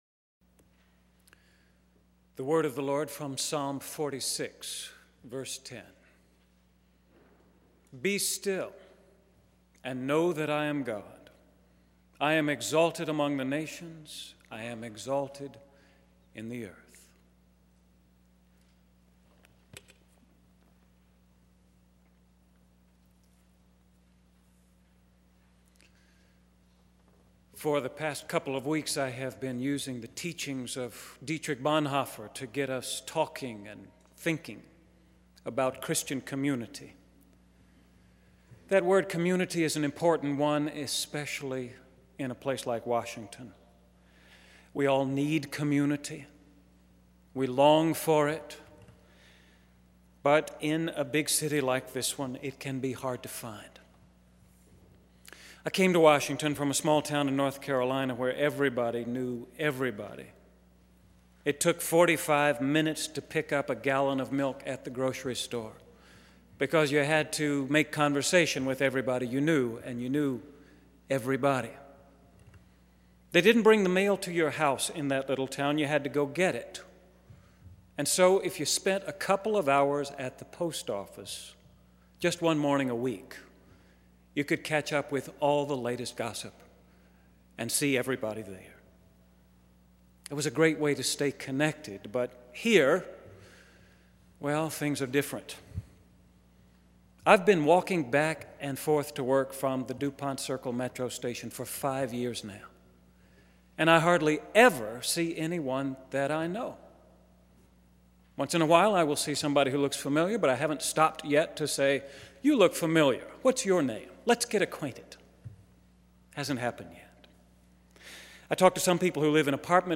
Third of a five-part sermon series based on the teachings of Dietrich Bonhoeffer.